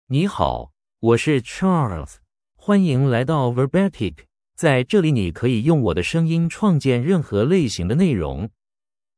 MaleChinese (Mandarin, Traditional)
Charles — Male Chinese AI voice
Voice sample
Male
Charles delivers clear pronunciation with authentic Mandarin, Traditional Chinese intonation, making your content sound professionally produced.